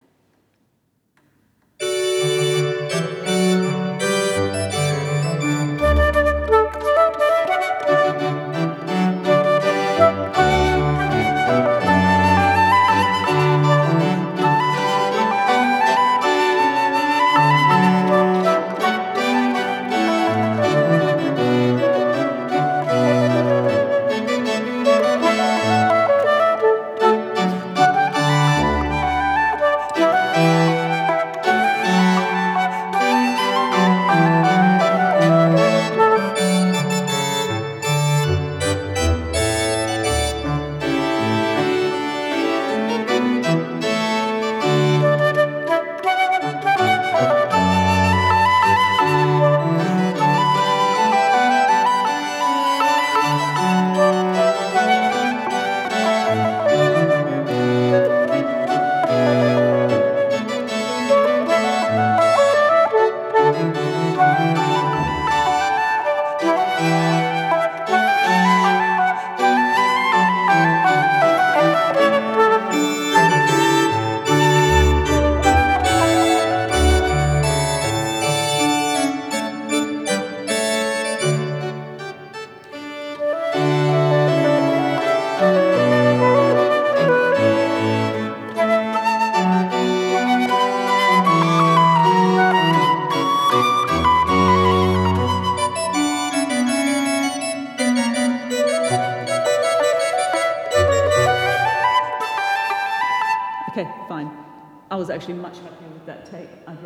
Flute music for weddings and other joyful worship occasions
INSTRUMENTATION: Flute and piano or organ